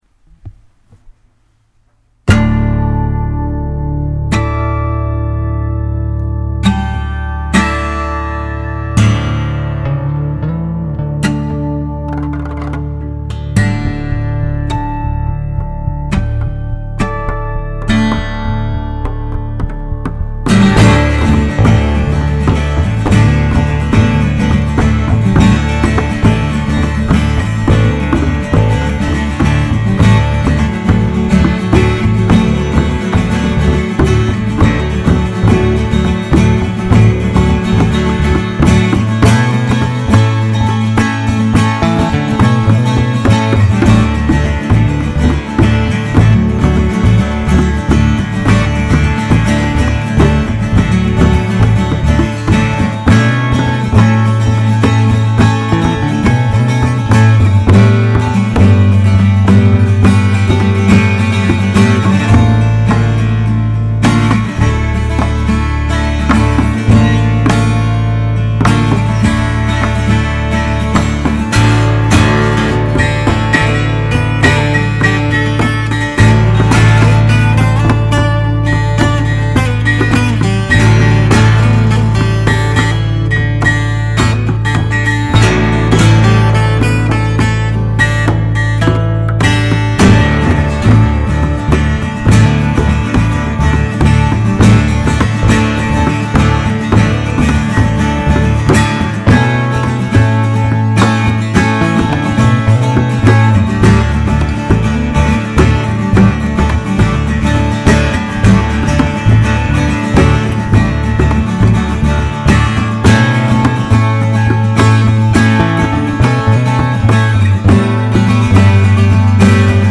that made a lot of sense last night when i was slugging it out with today’s release. i wanted to add bodhran and talking drum tracks to my 12-string and bass arrangement. i picked a nice clave and let it rock out for me. sadly, i really like to stretch out certain sections and i think you can really hear where in this recording. one take per track. i couldn’t push my luck with recording drums after the dude went to bed, so they are a little softer than i might like. still, i think it sounds ok.
criticism, music, original music